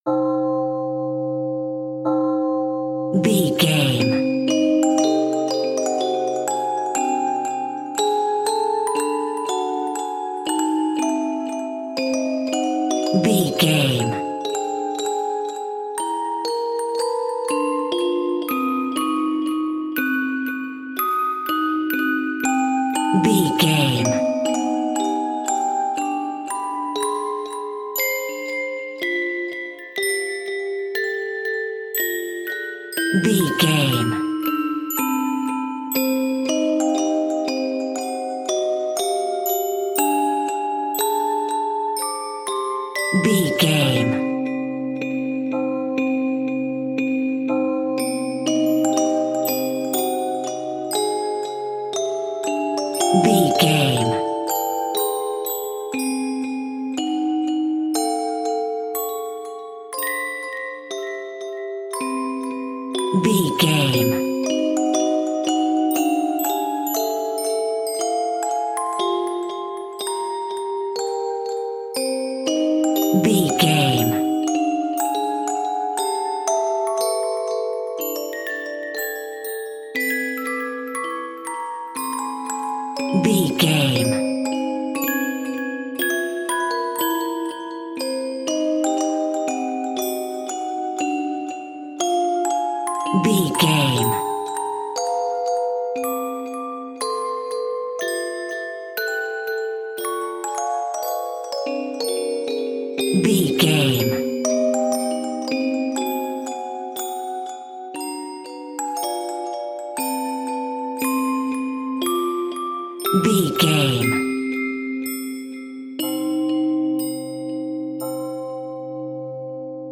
Ionian/Major
dreamy
eerie
ethereal
haunting
percussion
electric piano